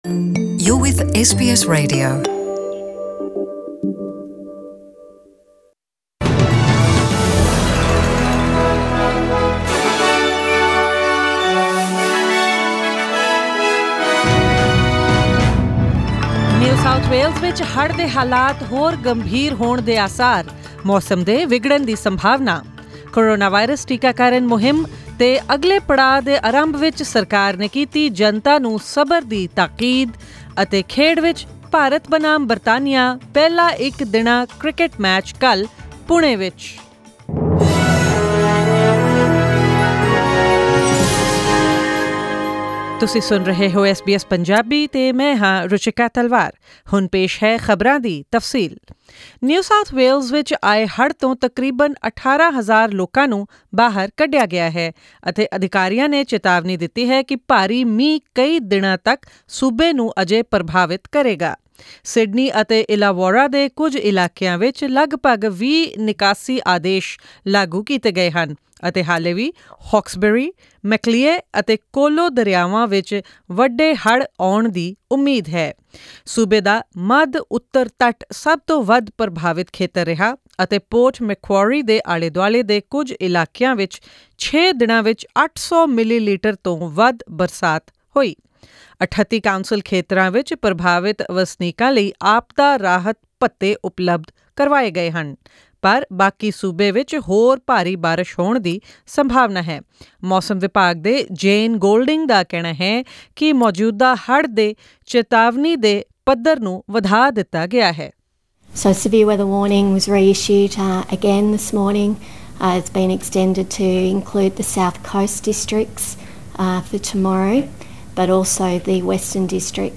Click the audio icon on the photo above to listen to the full news bulletin in Punjabi.